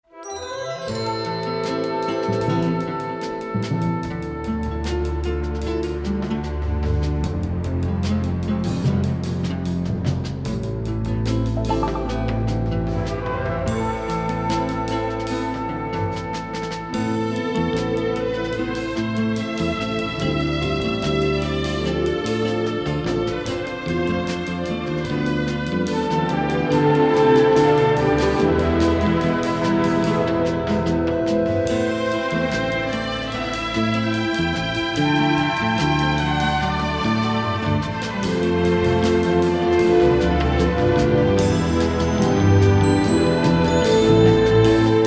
beautiful pop-flavored score